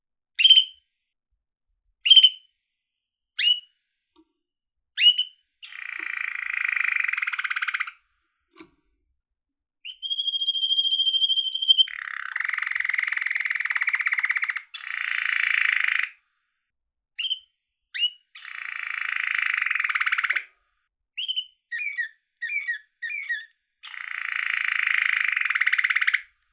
• Canary Trill Yellow.ogg
little yellow canary playing, trilling and chirping in his birdgage.
canary_trill_yellow_2w6.wav